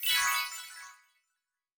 Special & Powerup (26).wav